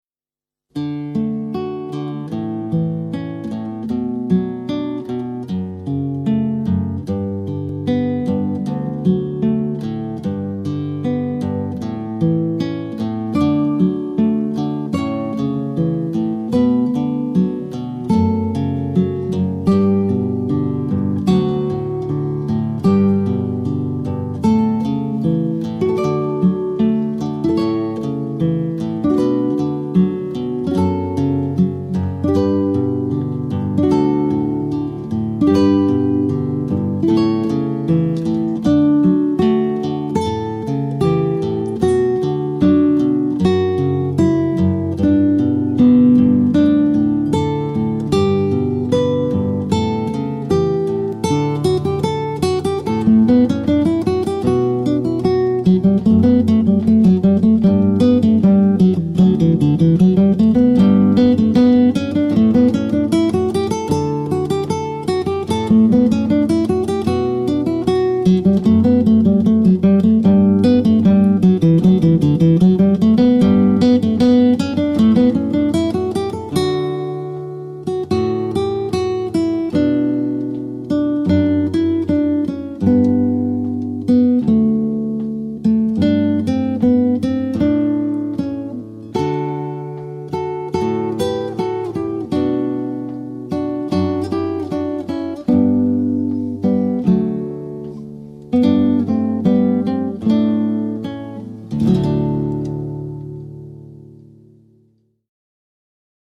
Solo Wedding Guitarist
• Solo
Acoustic guitar